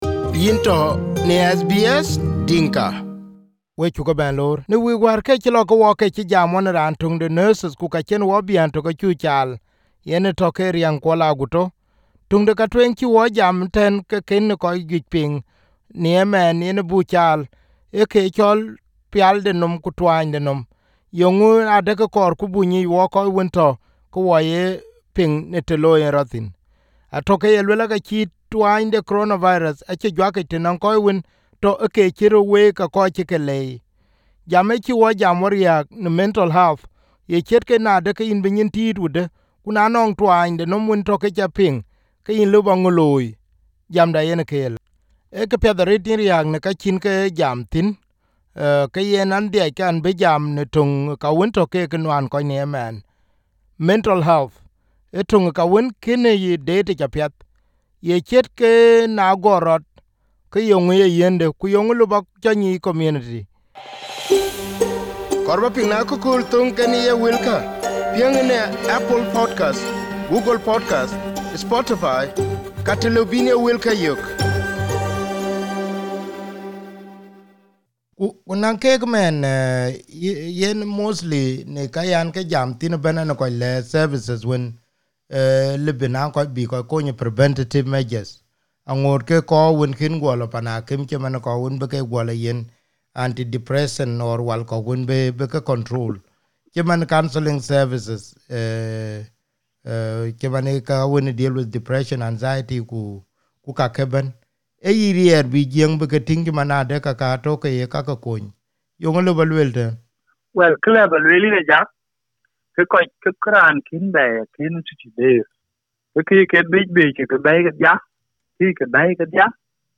This is part two of the interview.